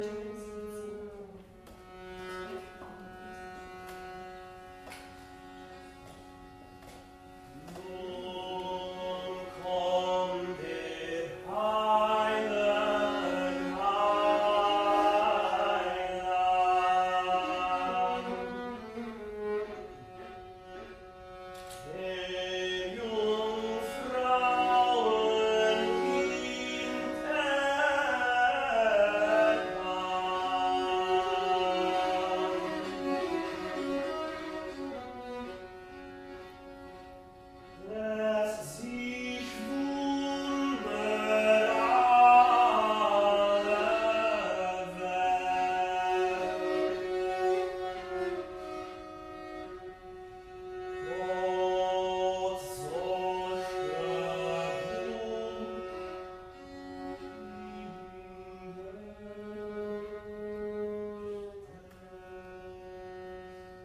Like on day 1 we use a chorale melody as our inspiration.